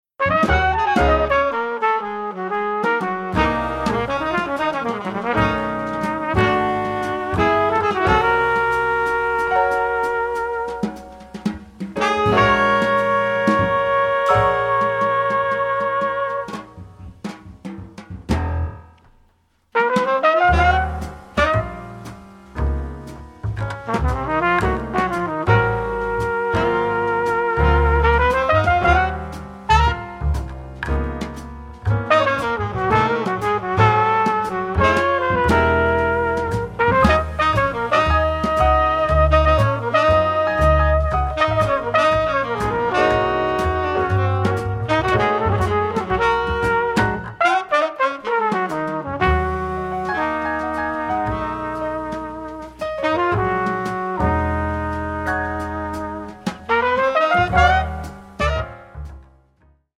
alto & tenor saxophone
piano
bass
drums